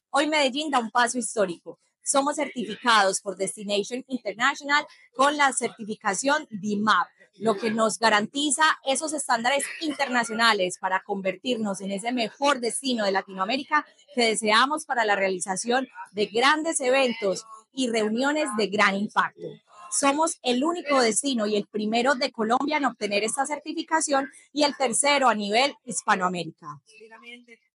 Audio Declaraciones